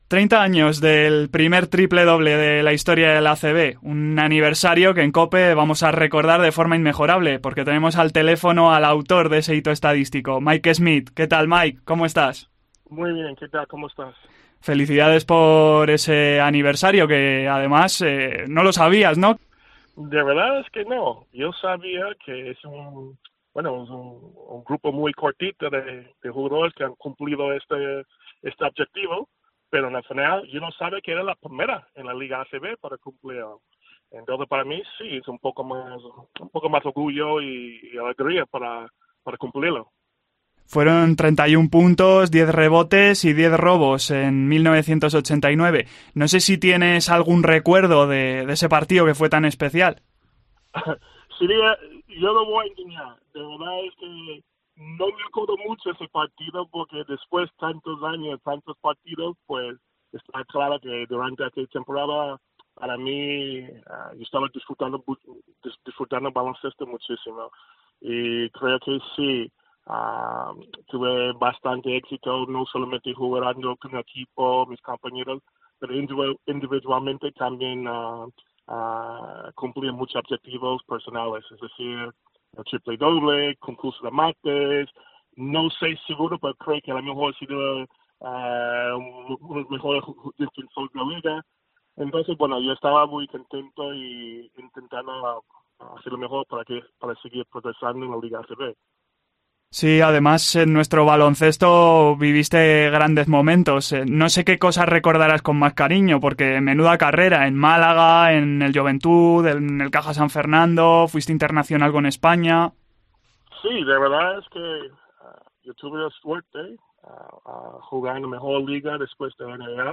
COPE entrevista a la leyenda del baloncesto español para festejar el aniversario de este hito estadístico de la ACB y recordar una carrera magistral